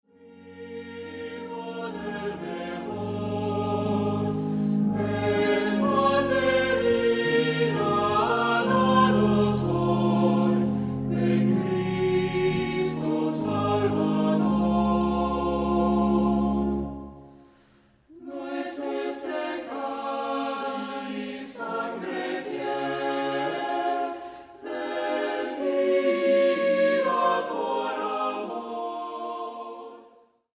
Voicing: SATB; Assembly